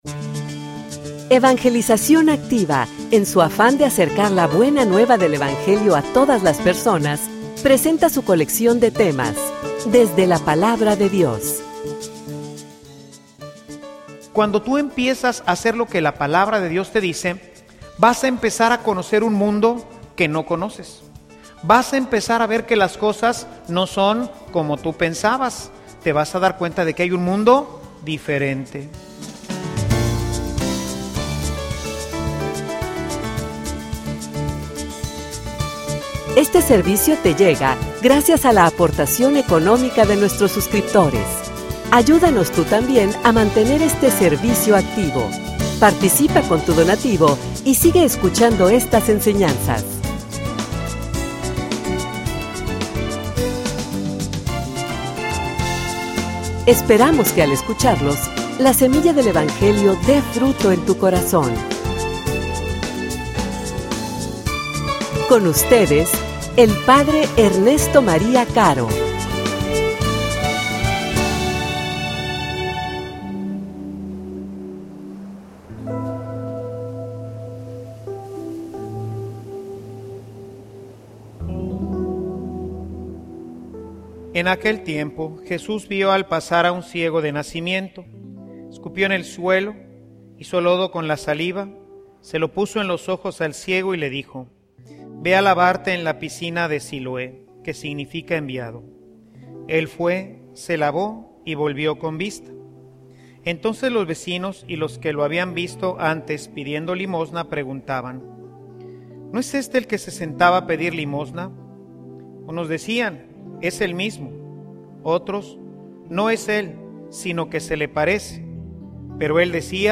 homilia_De_la_oscuridad_a_la_Luz.mp3